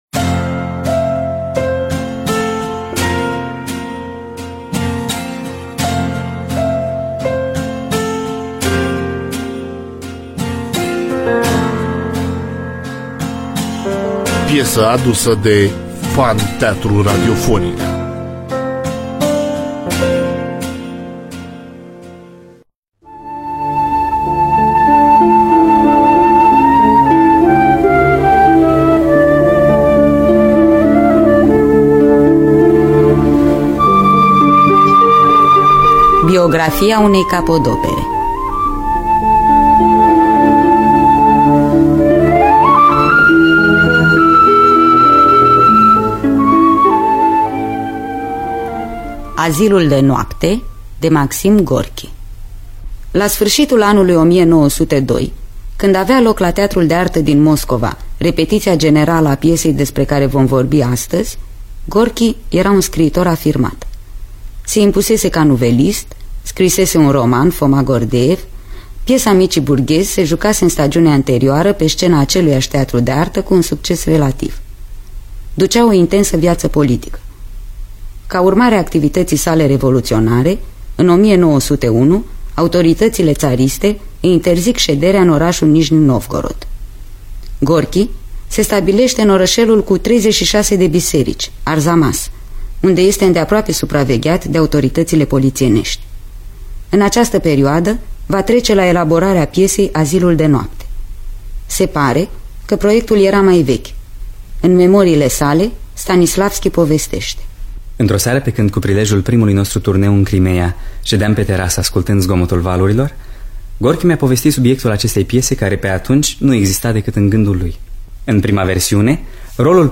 Biografii, Memorii: Maxim Gorki – Azilul De Noapte (1973) – Teatru Radiofonic Online